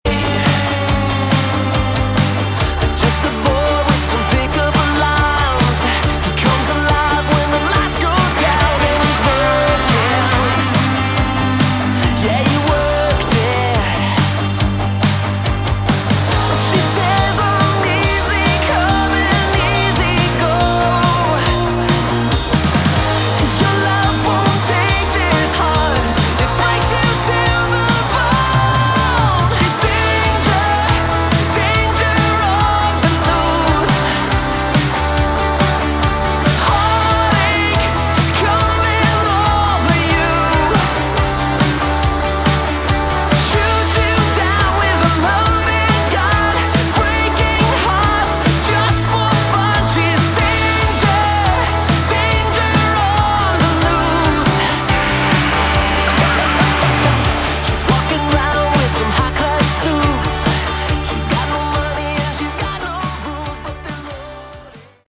Category: Melodic Rock / AOR
guitar, vocals
keyboards, vocals
bass